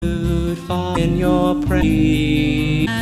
on my web site  I fell in love with its peaceful quality.